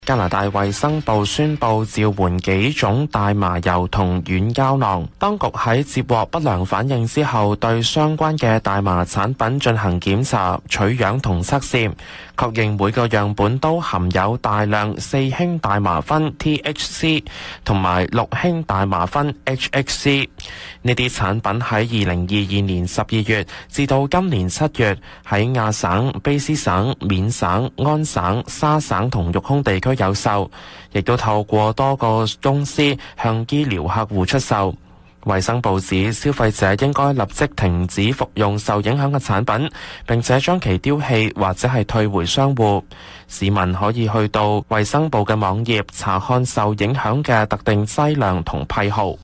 news_clip_20107.mp3